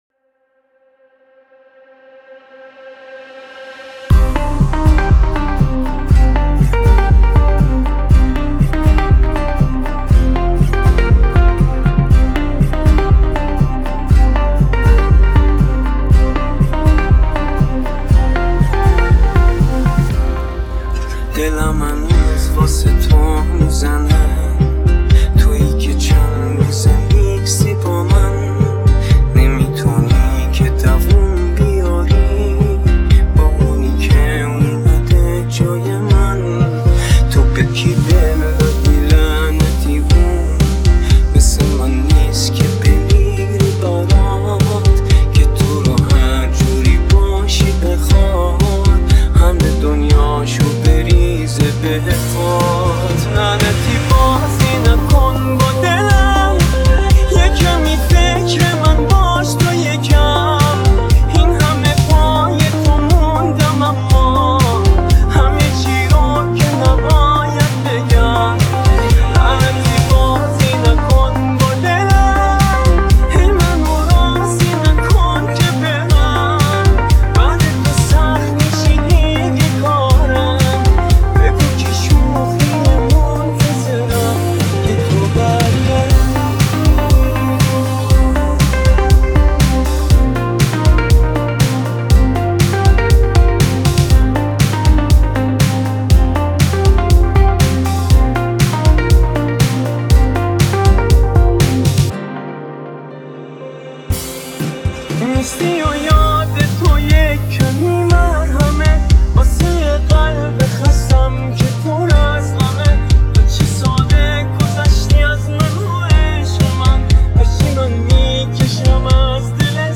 پاپ
آهنگ رپ